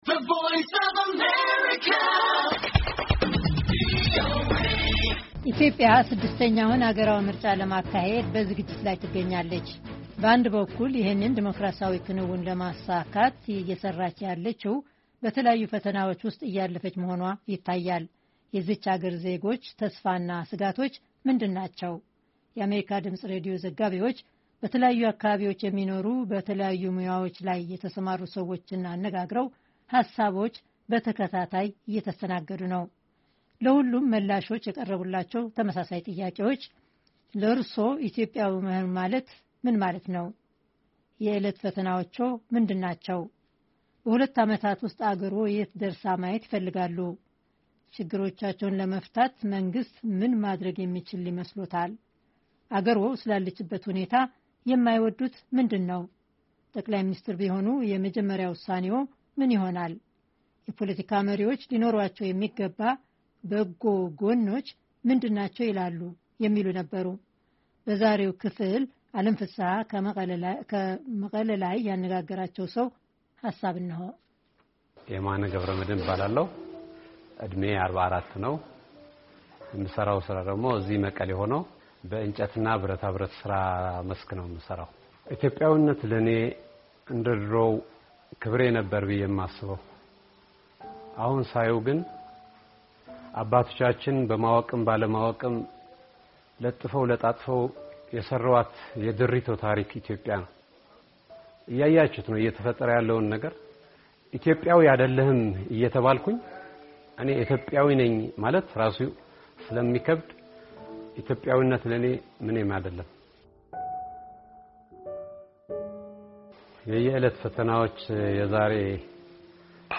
አሜሪካ ድምፅ ሬዲዮ ዘጋቢዎች በተለያዩ አካባቢዎች የሚኖሩ በተለያዩ ሙያዎች ላይ የተሠማሩ ሰዎችን አነጋግረው ሃሳቦቹ በተከታታይ እየተስተናገዱ ነው።